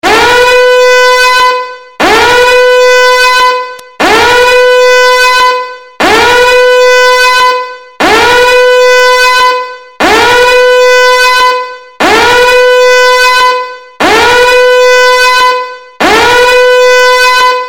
Nada Dering Alarm Tsunami untuk Grab, Gojek, Maxim…
Keterangan: Suara nada dering ini unik, lucu, sekaligus bikin heboh ketika ada panggilan masuk atau notifikasi WA.
Dengan nada dering alarm tsunami, HP kamu langsung terdengar khas, gampang dikenali, dan pastinya bikin ngakak.
nada-dering-alarm-tsunami-untuk-grab-gojek-maxim-id-www_tiengdong_com.mp3